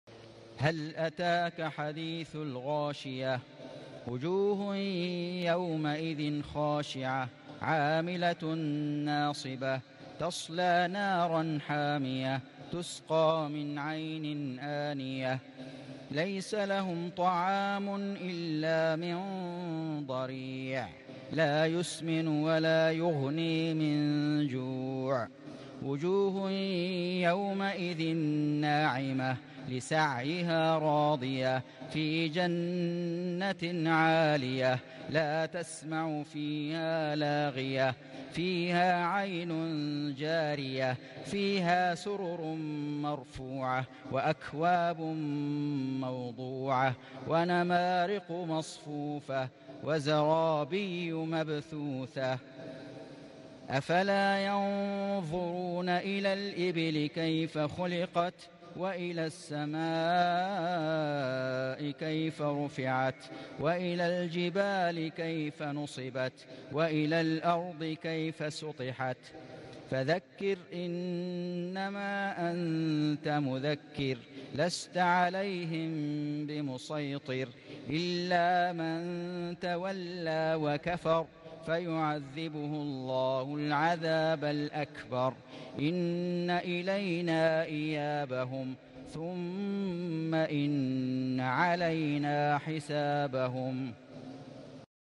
سورة الغاشية > السور المكتملة للشيخ فيصل غزاوي من الحرم المكي 🕋 > السور المكتملة 🕋 > المزيد - تلاوات الحرمين